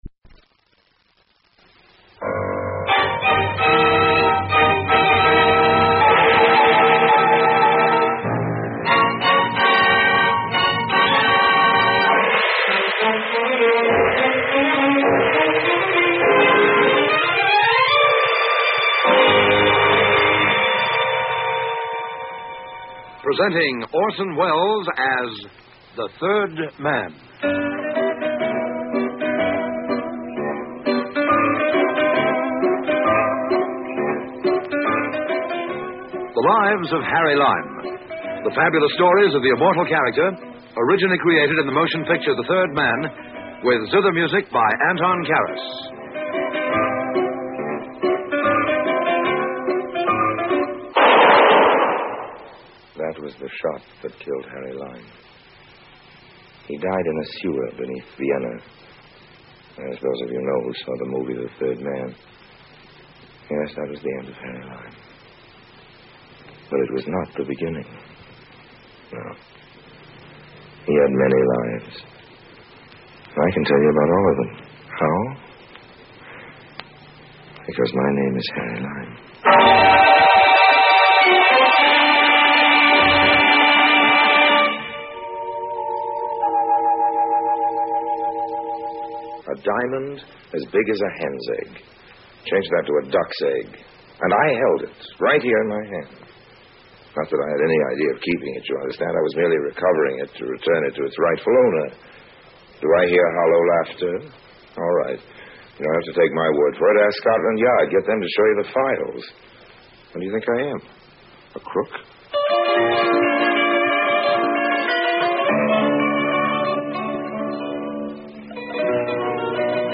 The Adventures of Harry Lime is an old-time radio programme produced in the United Kingdom during the 1951 to 1952 season. Orson Welles reprises his role of Harry Lime from the celebrated 1949 film The Third Man. The radio series is a prequel to the film, and depicts the many misadventures of con-artist Lime in a somewhat lighter tone than that of the film.